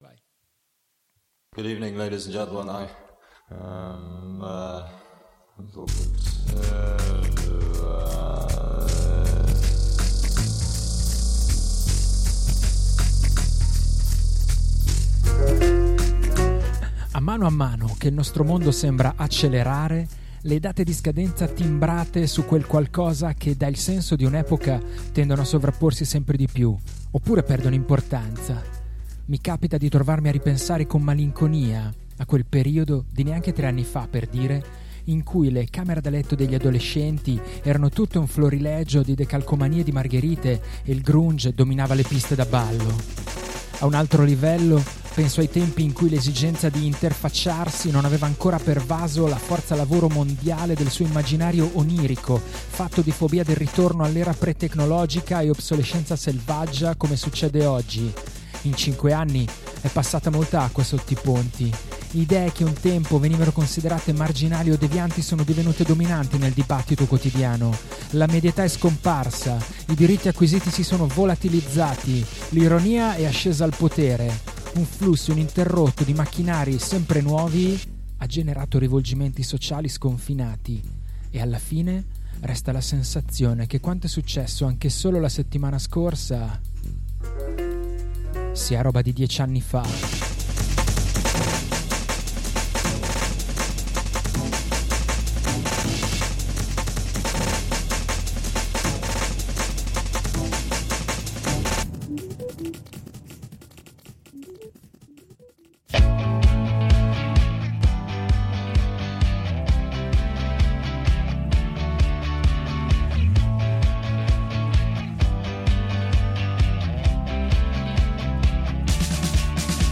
Indiepop, indie rock e brindisi!